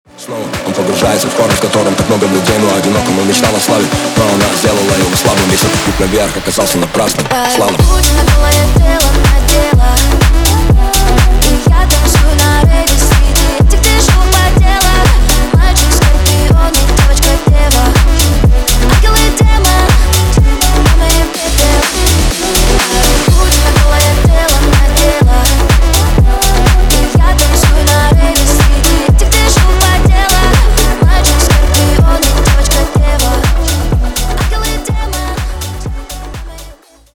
на русском клубные